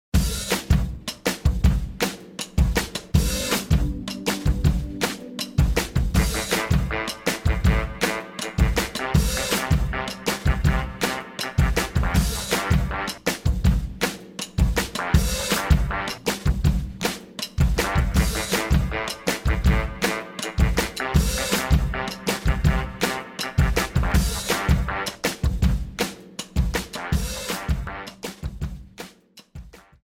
Without Backing Vocals. Professional Karaoke Backing Track.